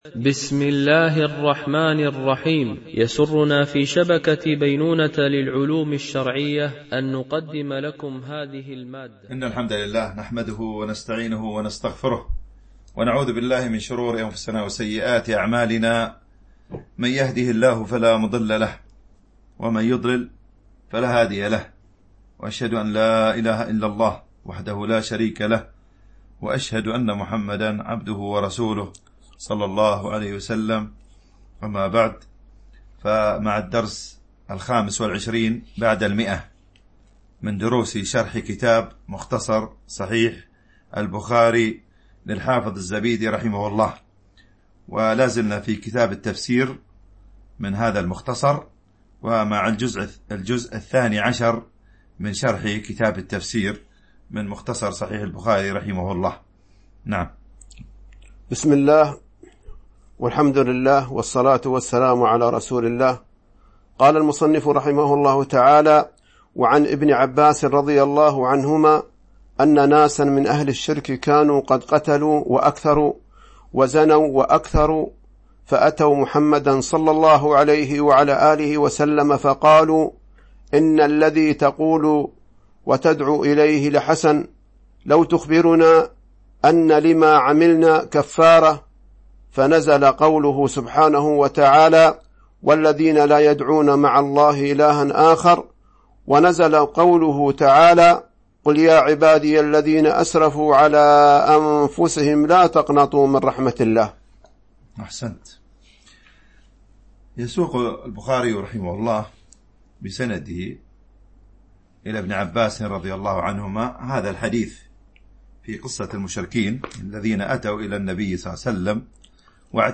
شرح مختصر صحيح البخاري ـ الدرس 125 ( كتاب التفسير ـ الجزء الثاني عشر ـ الحديث 1769 – 1775 )